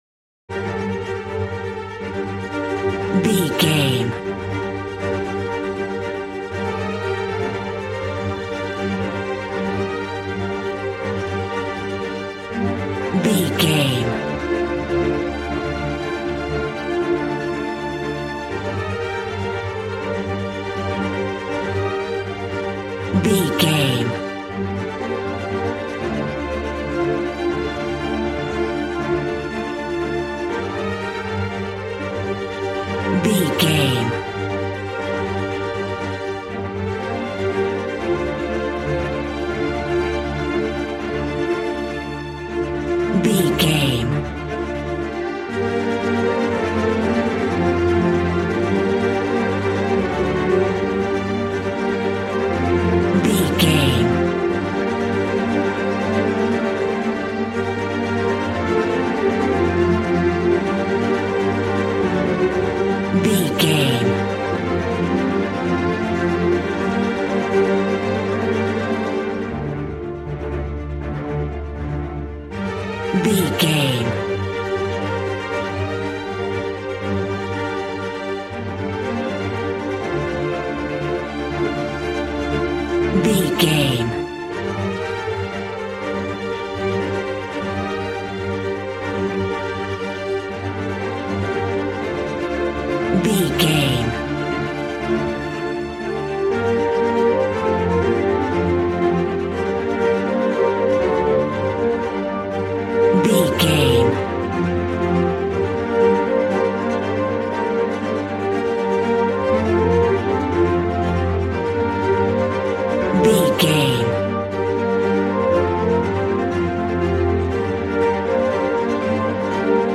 A classical music mood from the orchestra.
Regal and romantic, a classy piece of classical music.
Aeolian/Minor
cello
violin
strings